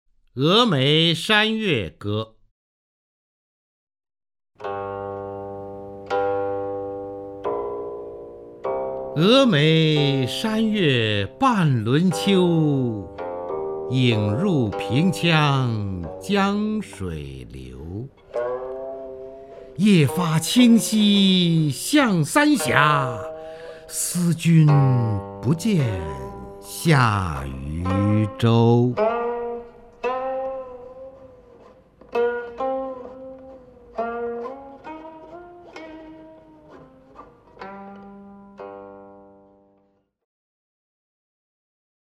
方明朗诵：《峨眉山月歌》(（唐）李白) （唐）李白 名家朗诵欣赏方明 语文PLUS